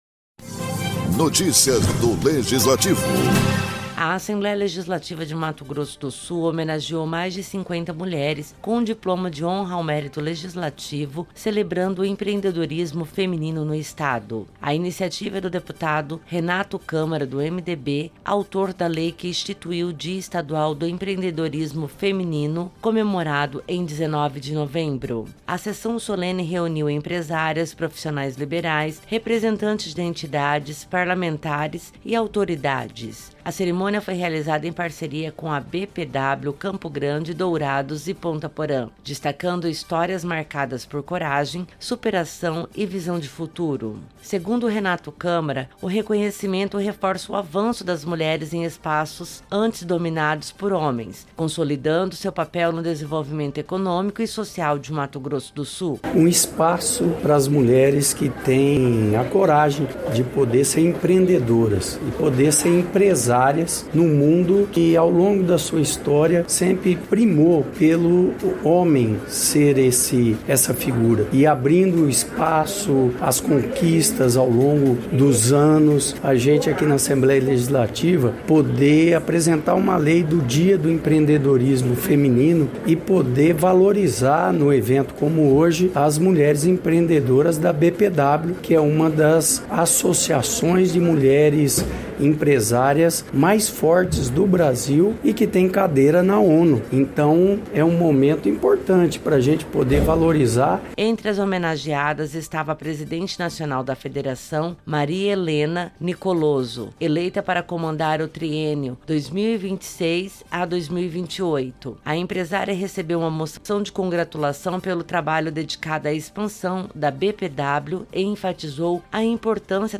No Plenário Deputado Júlio Maia, a Assembleia Legislativa de Mato Grosso do Sul homenageou e reconheceu a força das mulheres empreendedoras do Estado.